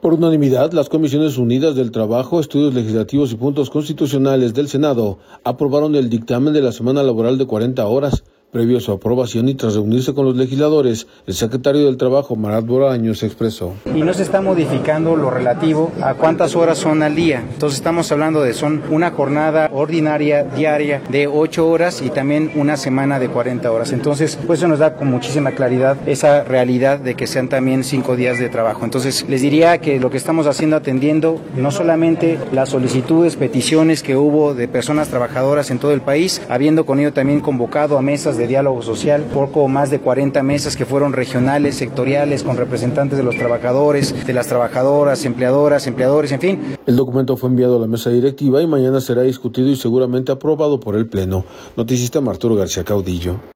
Por unanimidad, las comisiones unidas del Trabajo, Estudios Legislativos y Puntos Constitucionales de Senado, aprobaron el dictamen de la semana laboral de 40 horas. Previo a su aprobación y tras reunirse con los legisladores, el secretario del Trabajo, Marath Bolaños, expresó.